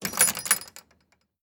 Doors Gates and Chests
Gate Close.wav